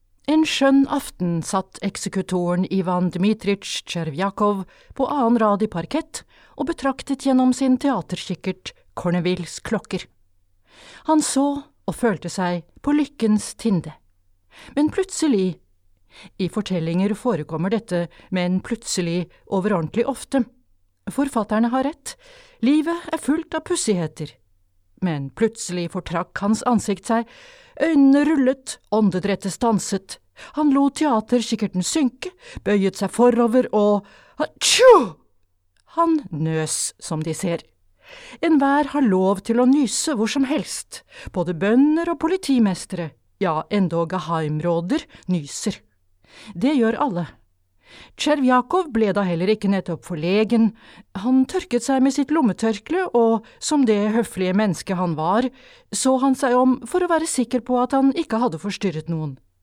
En embedsmanns død (lydbok) av Anton Tsjekhov